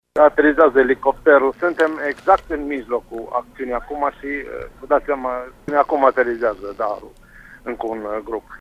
Ambele elicoptere au și aterizat deja la Bâlea Lac și au fost îmbarcați primii turiști, 23 la număr , ne-a declarat, în urmă cu câteva minute, prefectul de Sibiu, Ioan Sitterli: